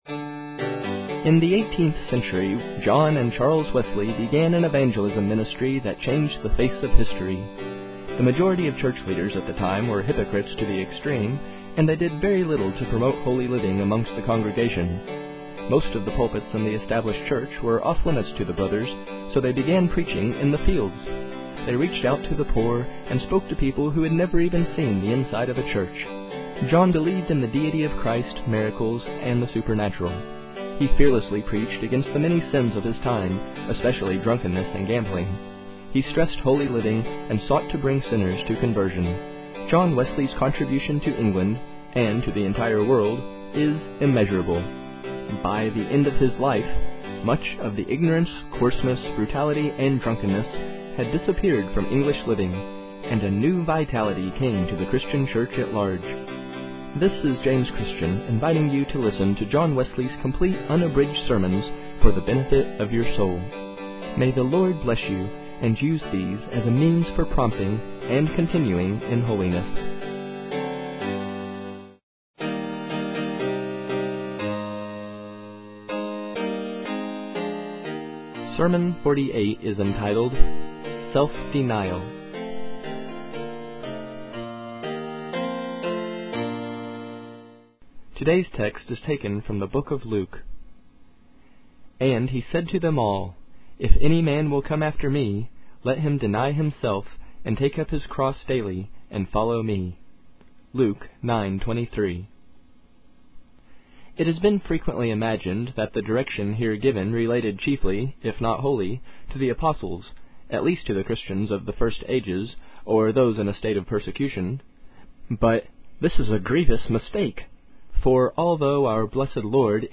Self Denial (Reading) by John Wesley | SermonIndex